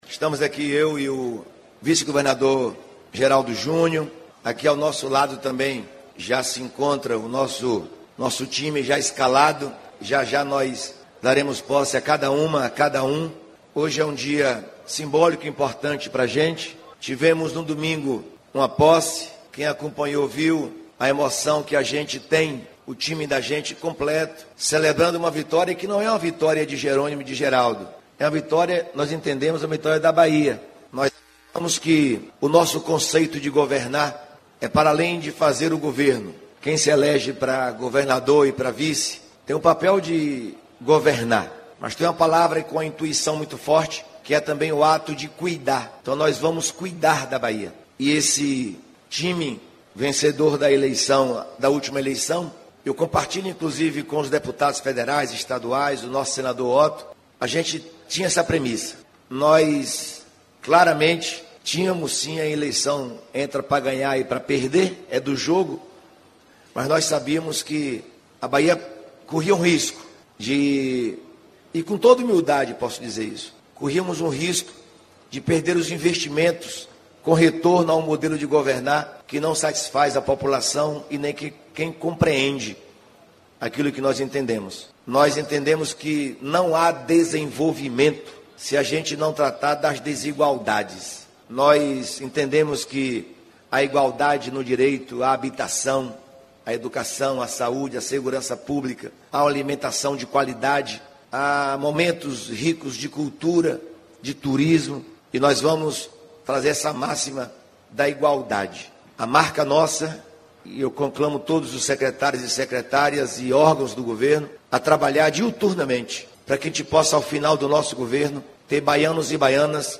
Em entrevista coletiva concedida na manhã desta terça-feira (03), o governador Jerônimo Rodrigues falou sobre os principais focos da sua gestão, reafirmou o compromisso de combater a fome e, ao lado seu vice, Geraldo Júnior, e dos secretários e secretárias estaduais, frisou: “nós vamos cuidar da Bahia”.